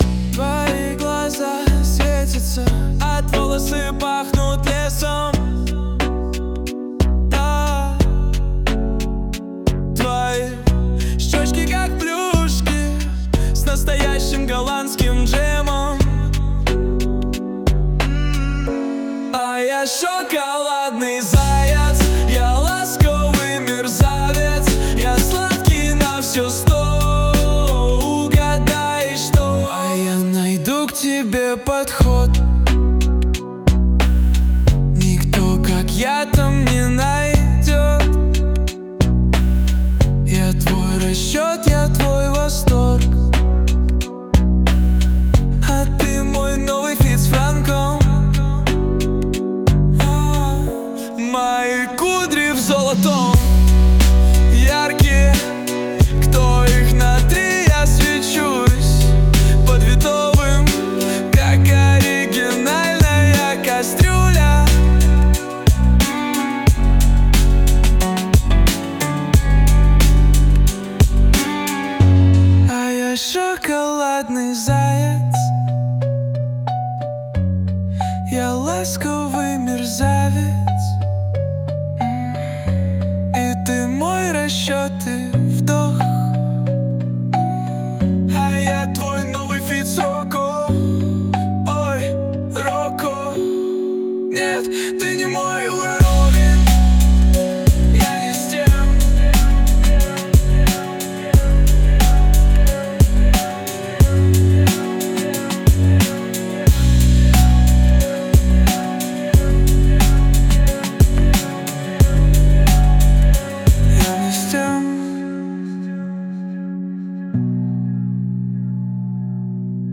RUS, Romantic, Rap | 17.03.2025 16:30